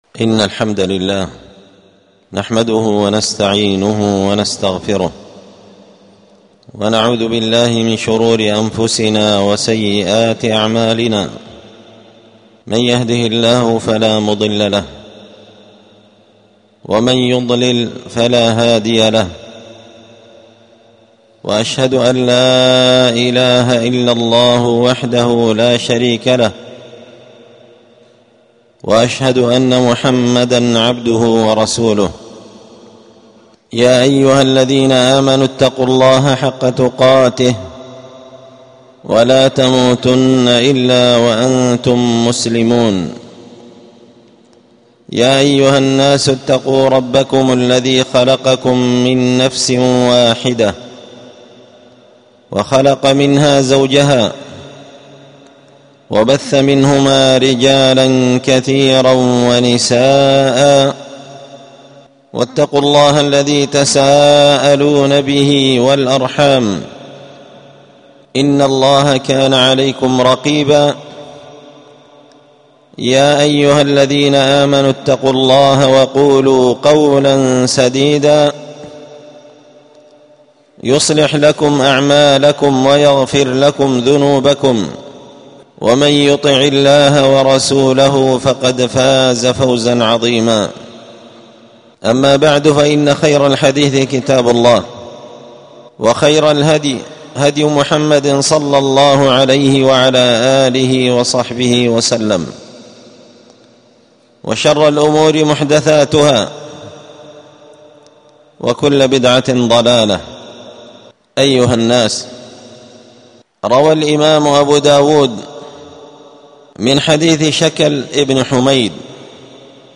ألقيت هذه الخطبة بدار الحديث السلفية بمسجد الفرقان
الجمعة 10 رمضان 1447 هــــ | الخطب والمحاضرات والكلمات | شارك بتعليقك | 92 المشاهدات